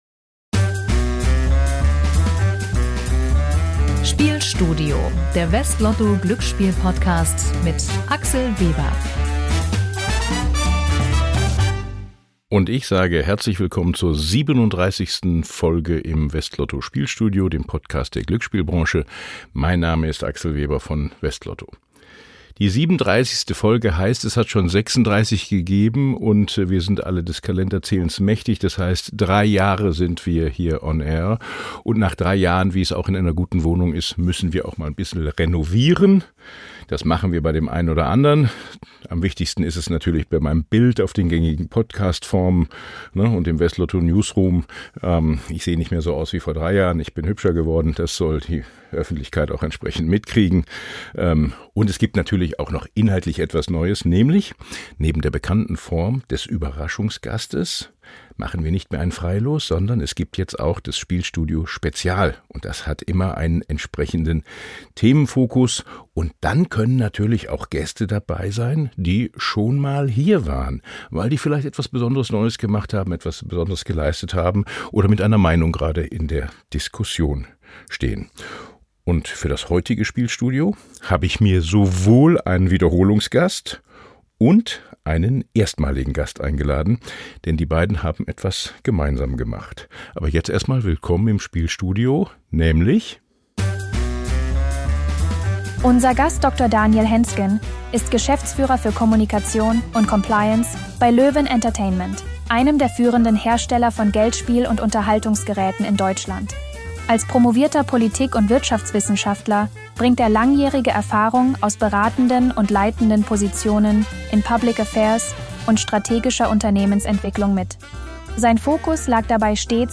Hinweis: Manche Zuspieler in diesem Podcast wurden mit einer künstlichen Stimme erzeugt, die von einem KI-System erstellt wurde.